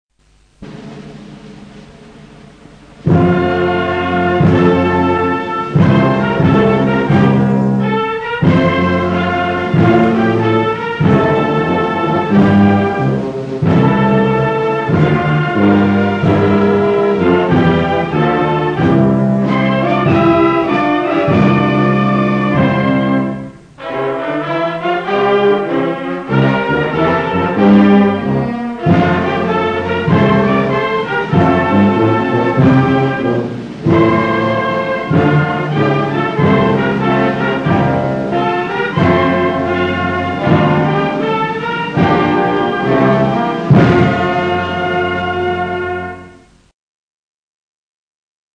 ns_instrumental.mp3